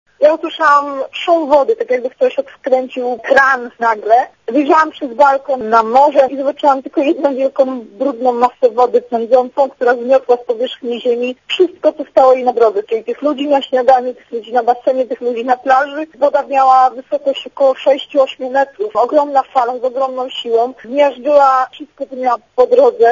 tsunami-polka.mp3